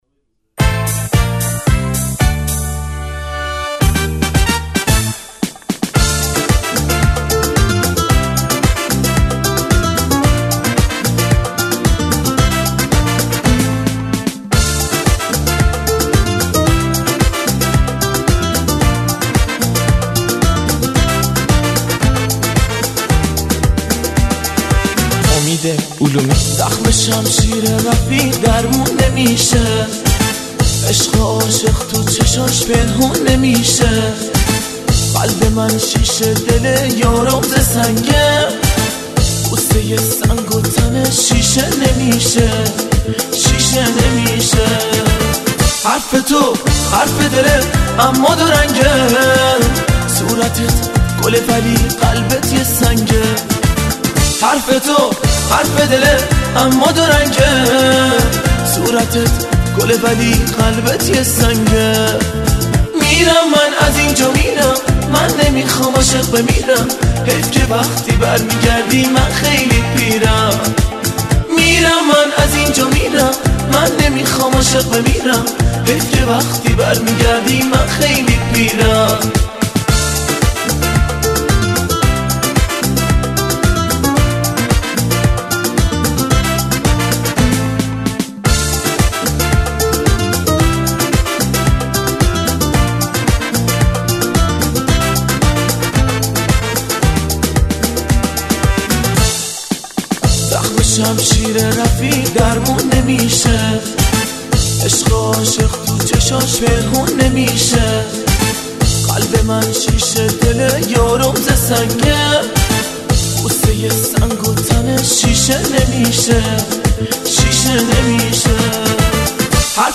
آهنگ غمگین جدید